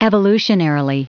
Prononciation du mot evolutionarily en anglais (fichier audio)
Prononciation du mot : evolutionarily